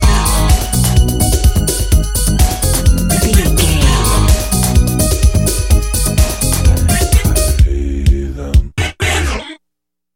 Aeolian/Minor
E♭
synthesiser
Eurodance